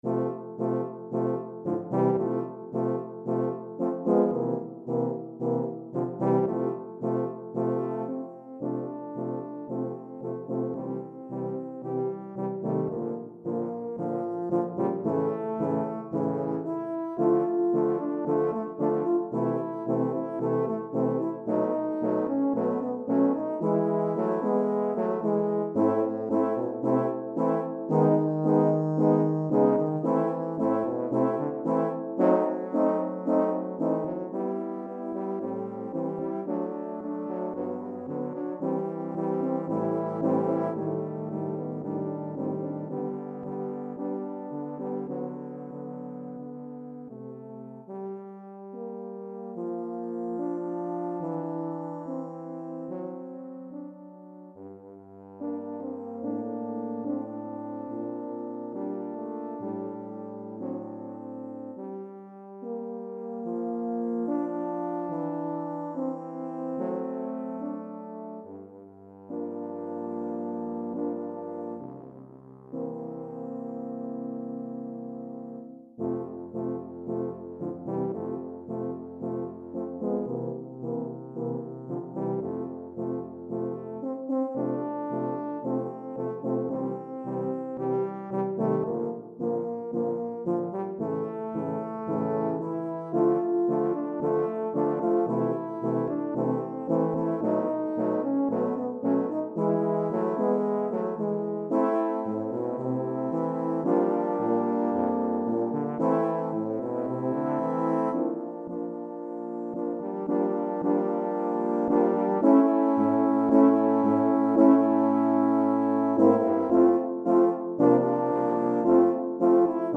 Pour quatuor d’euphoniums ou saxhorns DEGRE CYCLE 1